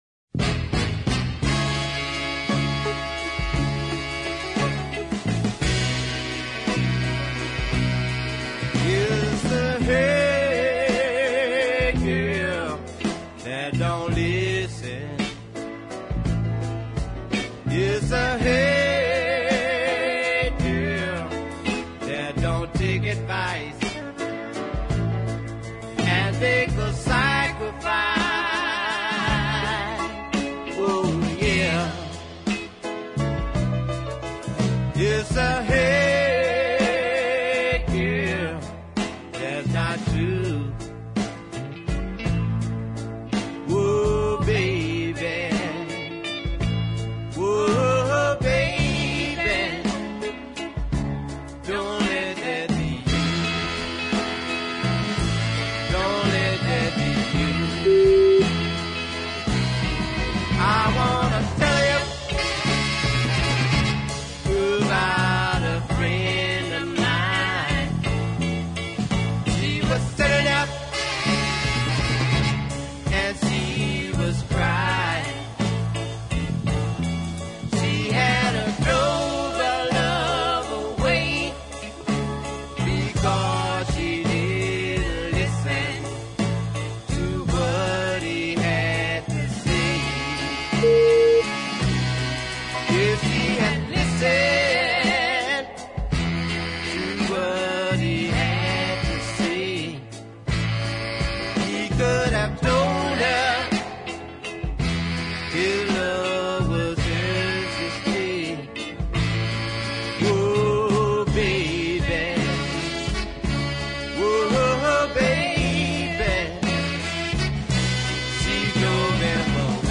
lovely deep soul ballad